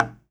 Knock25.wav